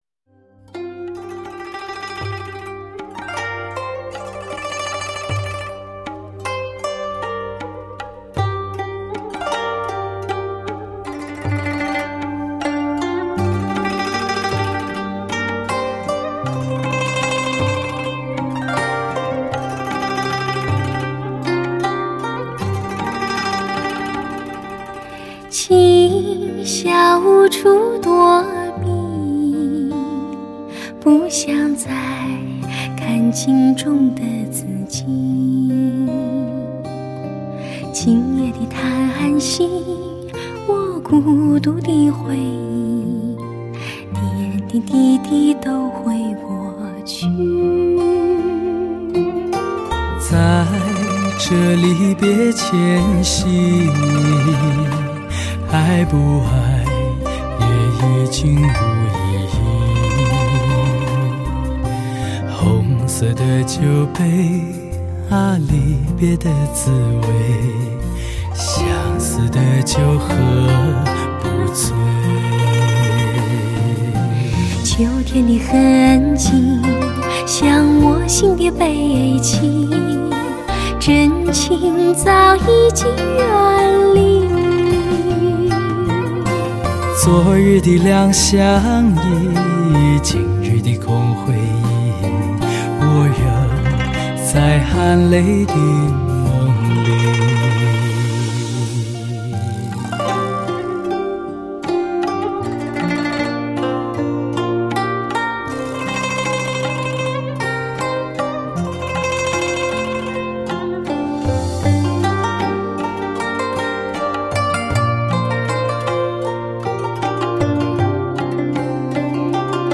男女对唱经典 那个季节里的歌（八）
震撼发烧兼具内涵完美录音
悠扬动听的演唱，怎能不令人缠绵于往事，